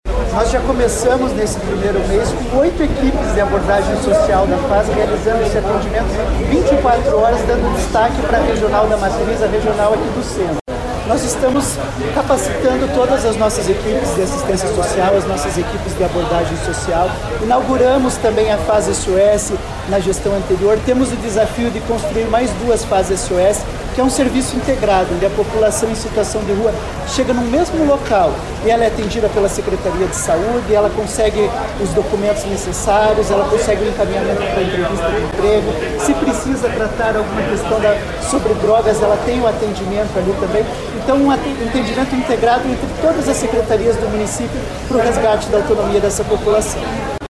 O presidente da Fundação de Ação Social, Renan de Oliveira Rodrigues, disse que várias ações já estão em andamento na instituição.